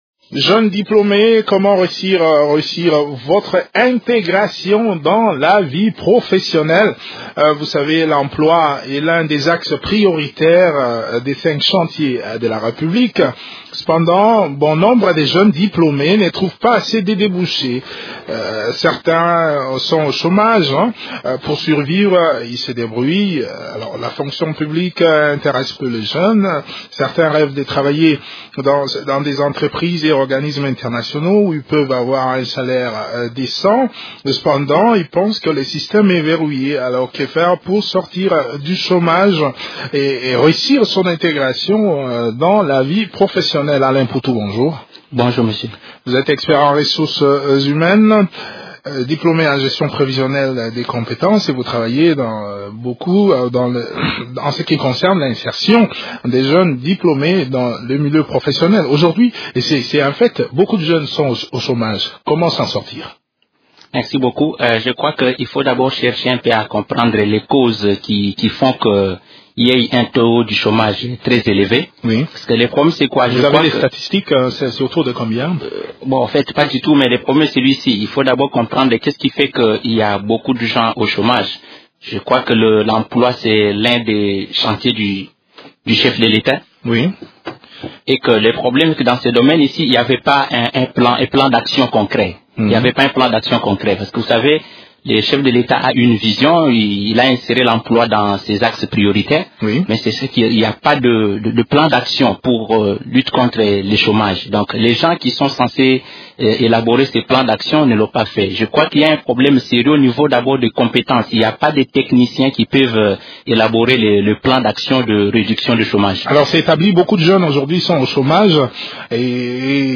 expert en ressources humaines